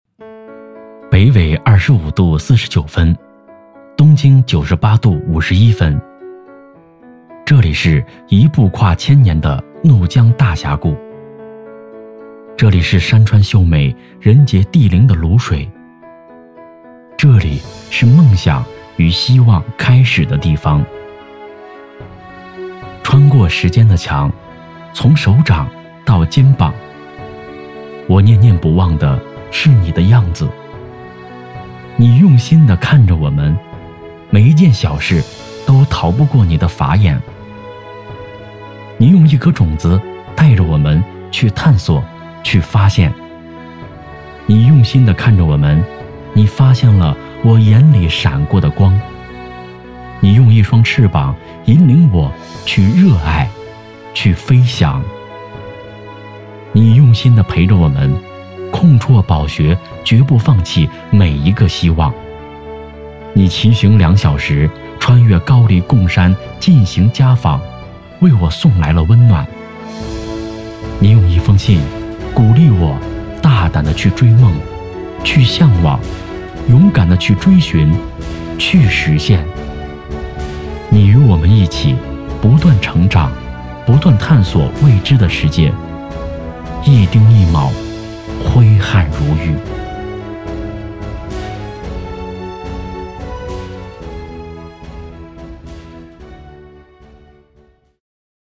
【男80号专题】泸水教师
【男80号专题】泸水教师.mp3